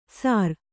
♪ sār